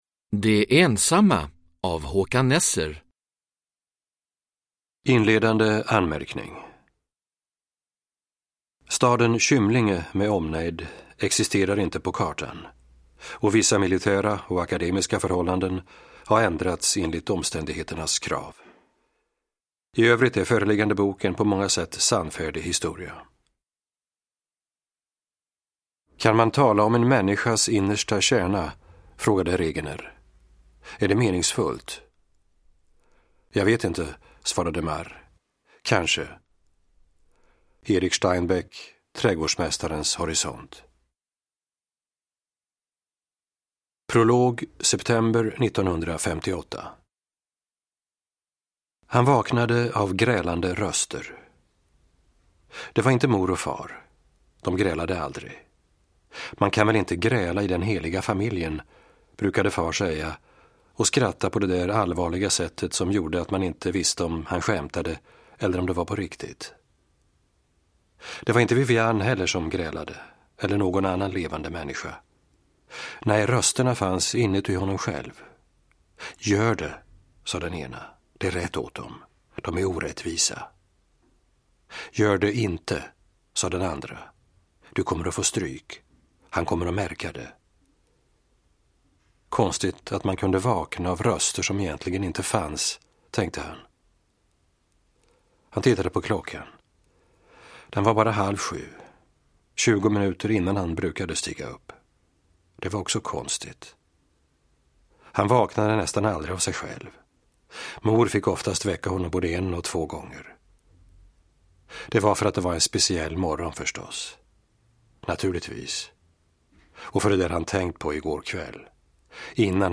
De ensamma – Ljudbok – Laddas ner
Uppläsare: Håkan Nesser